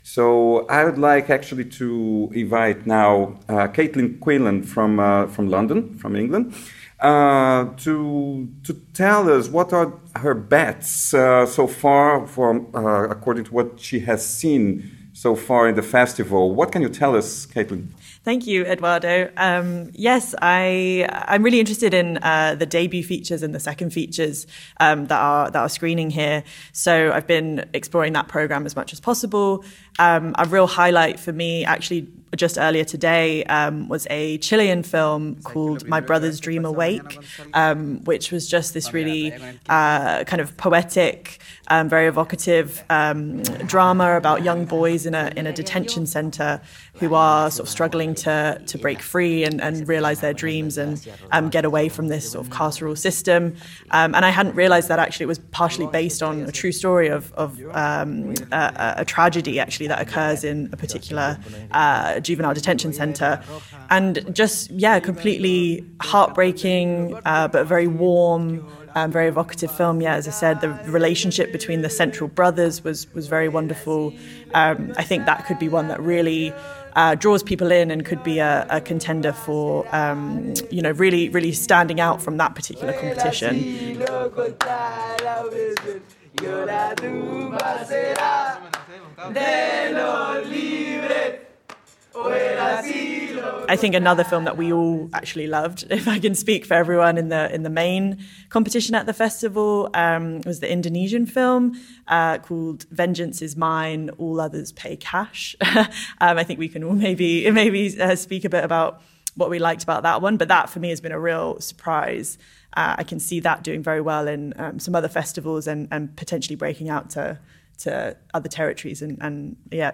Two women and two men discussing films